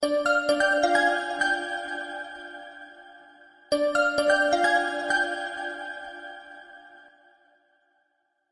Tono de llamada Melodía musical de suspenso